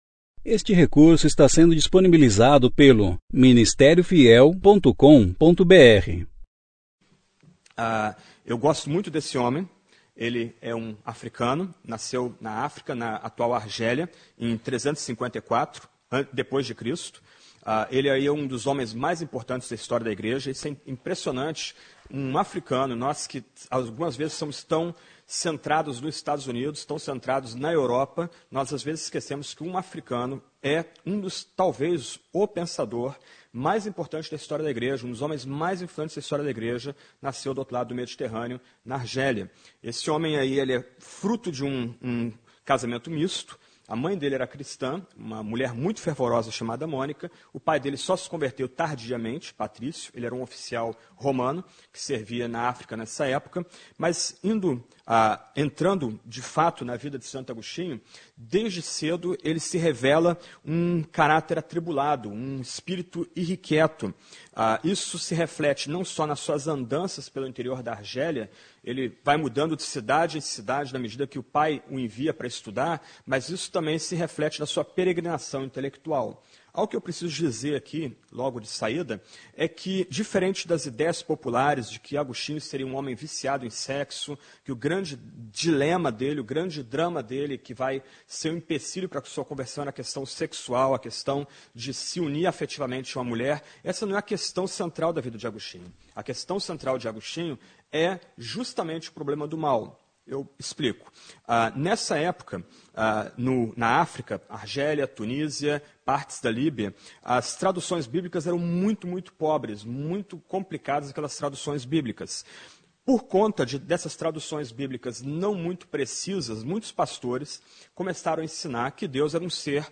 Conferência: 8ª Conferência Fiel – Portugal Tema: Pregação Poderosa Ano